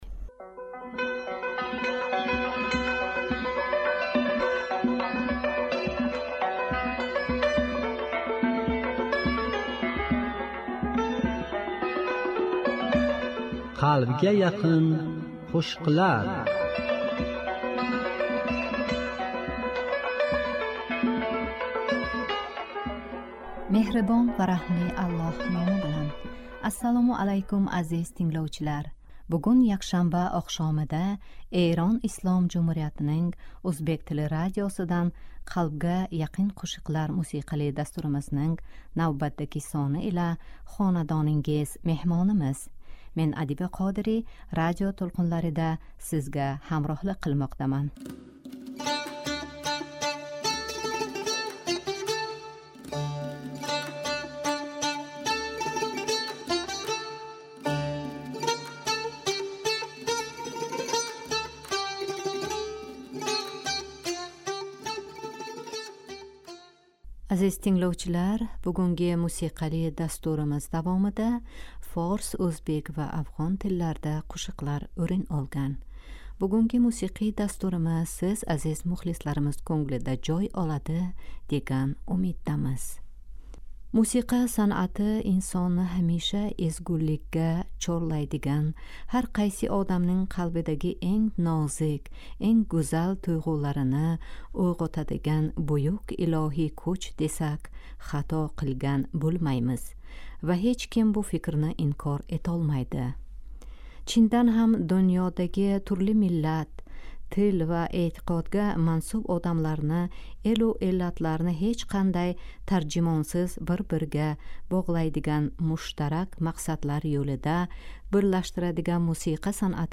Бугунги якшанбе оқшомида Эрон Ислом Жумҳуриятининг ўзбек тили радиосидан